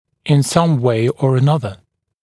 [ɪn sʌm weɪ ɔːr ə’nʌðə][ин сам уэй о:р э’назэ]тем или иным образом